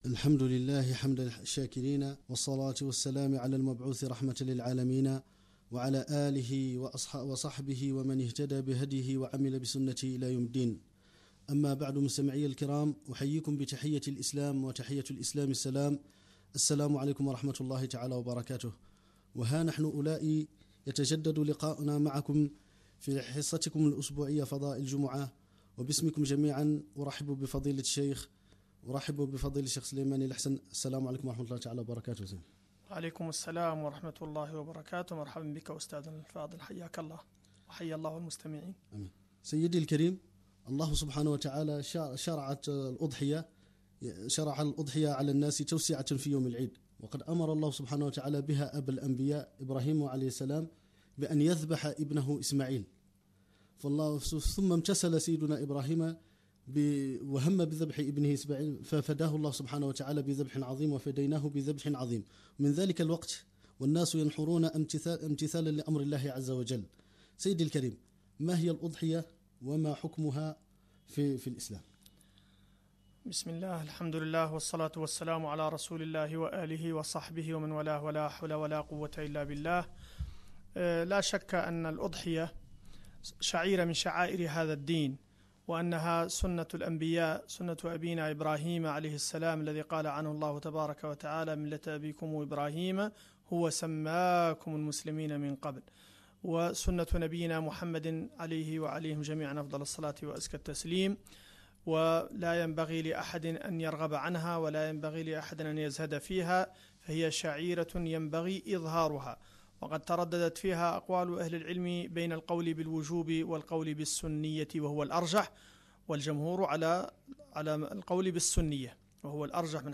برامج إذاعية البرنامج الإذاعي: فضاء الجمعة